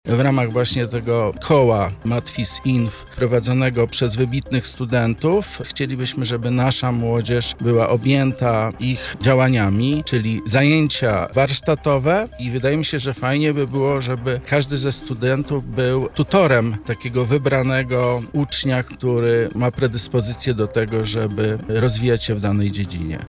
Poranna Rozmowa Radia Centrum